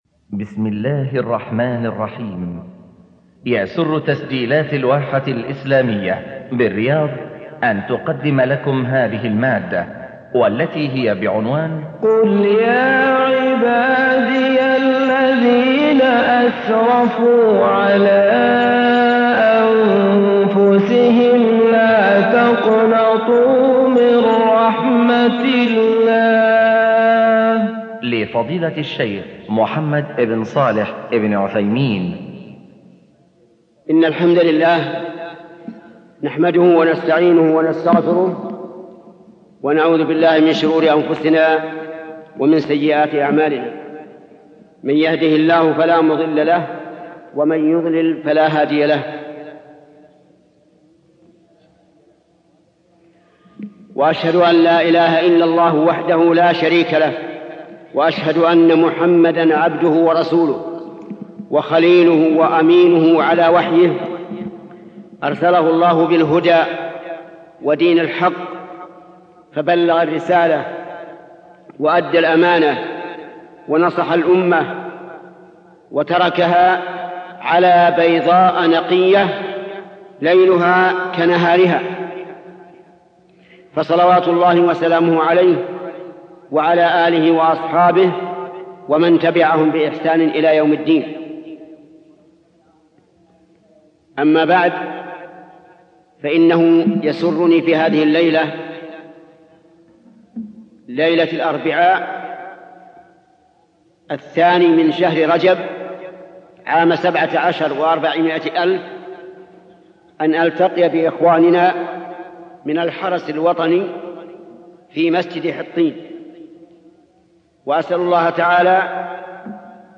من اجمل المحاضرات - قل يا عبادي اللذين اسرفو على انفسهم